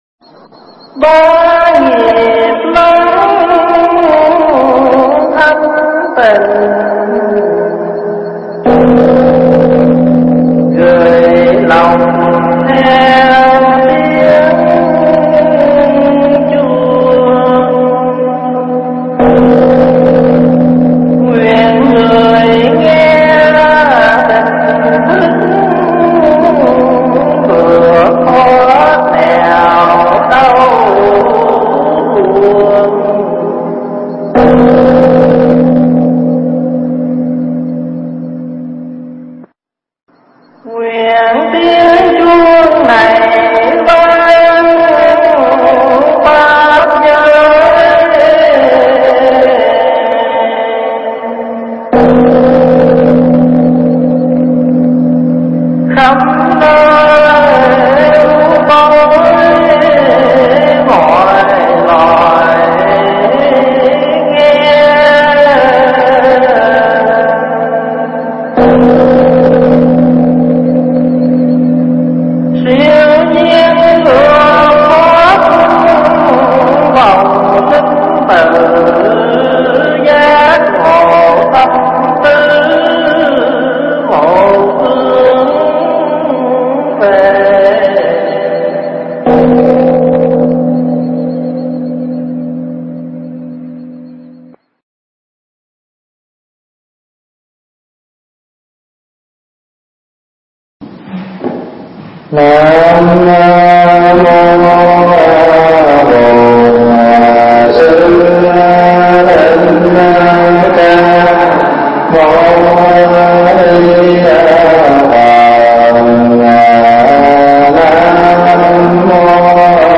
thuyết giảng tại Tu Viện Trúc Lâm, Canada, khóa tu phật pháp cuối tuần